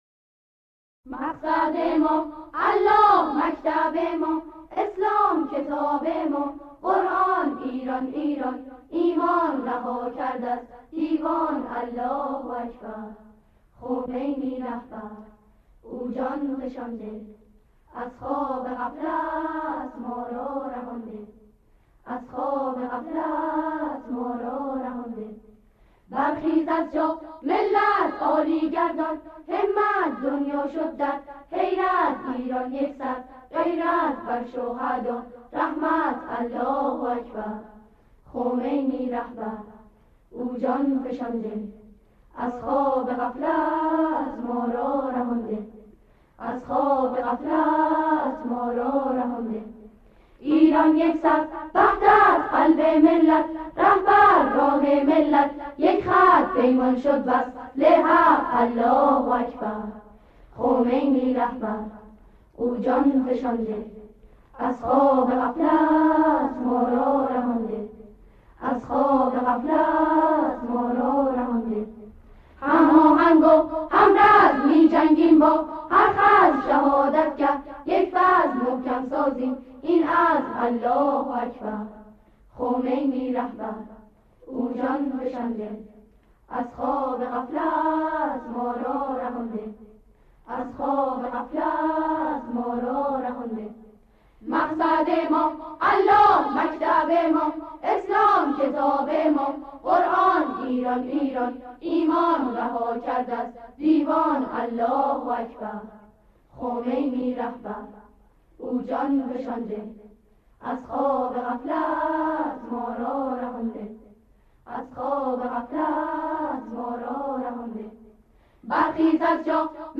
گروهی از نوجوانان انقلابی
به صورت آکاپلا اجرا کرده‌اند